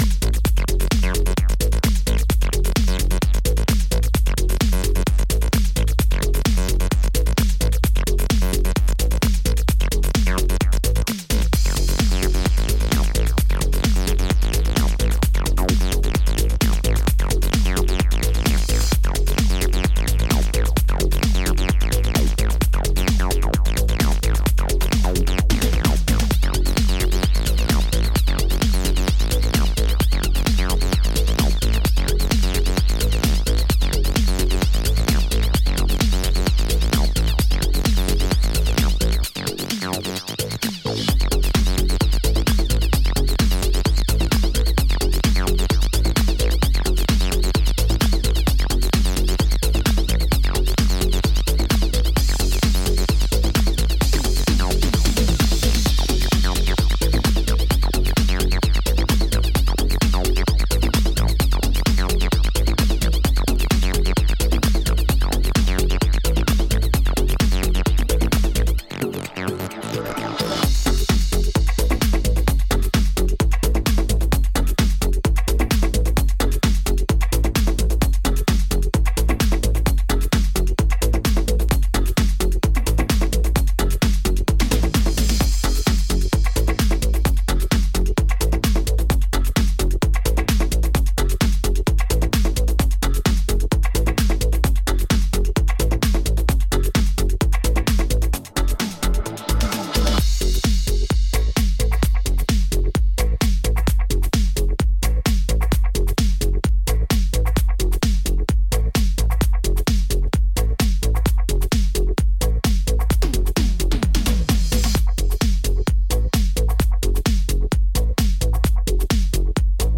ディスコティークなボトム&ベースラインで淡々とまとまった